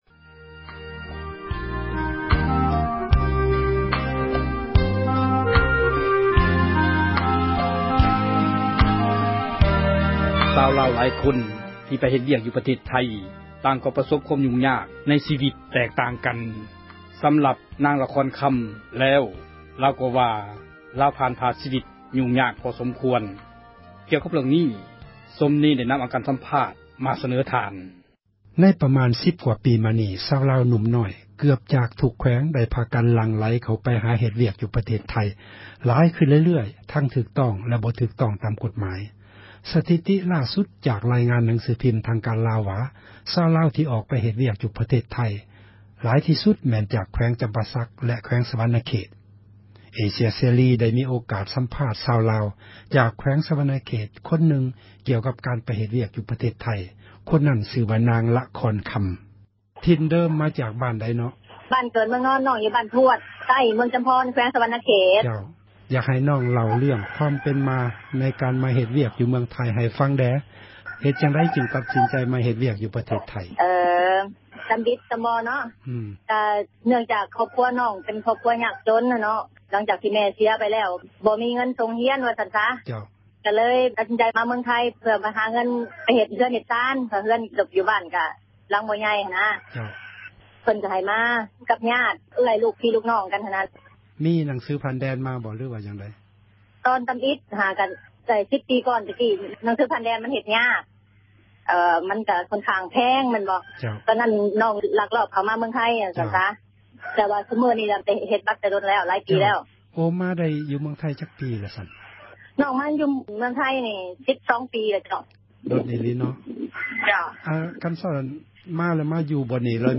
ສັມພາດຄົນງານລາວ ໃນໄທ